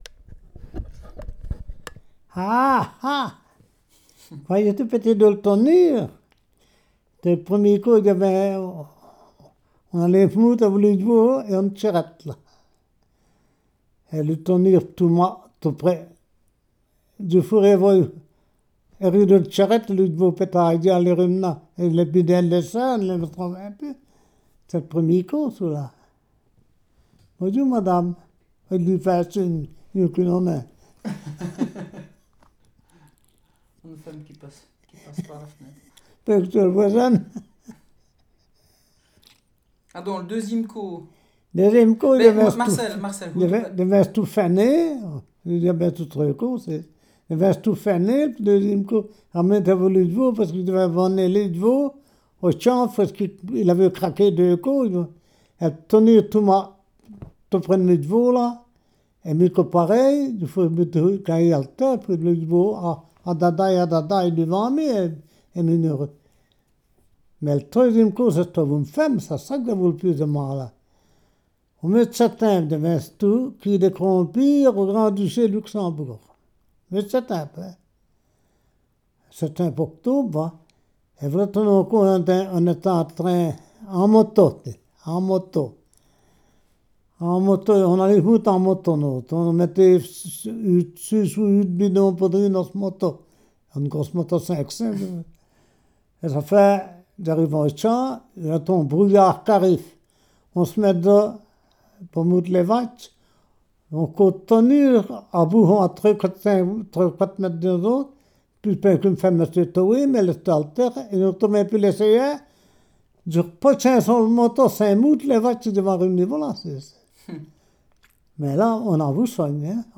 s'exprime dans le wallon de son village,
Aisomont (Ardenne belge)